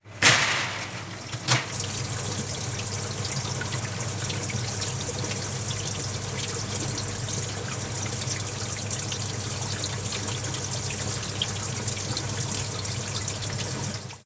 Lavavajillas de un bar (grande)